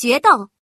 female
duel.mp3